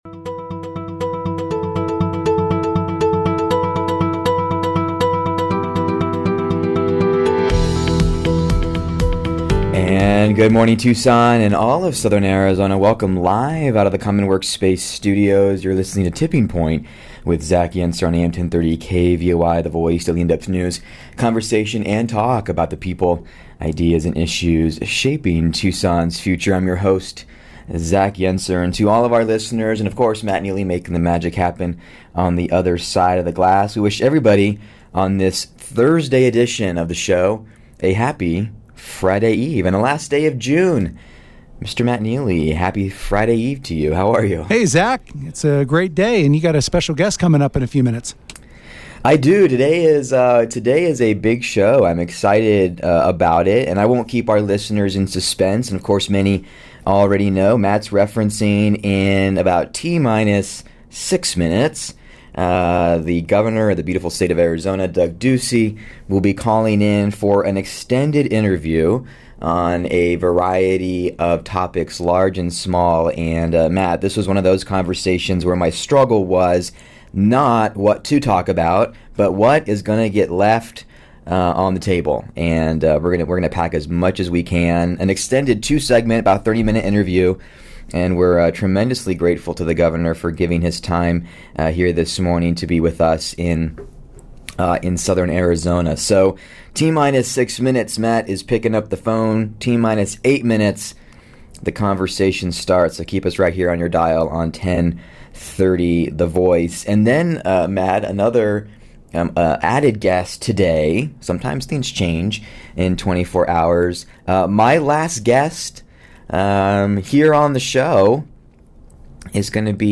Governor Doug Ducey, an extended interview